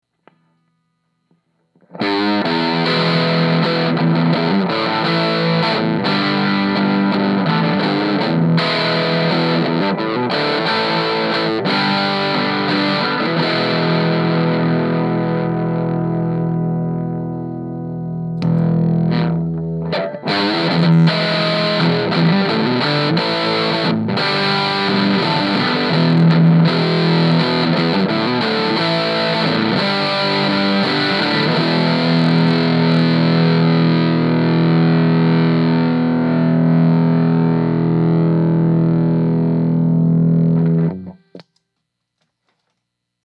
All the clean clips were recorded at unity volume, while the dirty clips were played with the amp turned up to about 2pm, and the boost at 1pm with Mix and Reverb completely off.
Les Paul Middle Pickup (biased toward bridge), Volume: 2pm, Mix/Reverb Off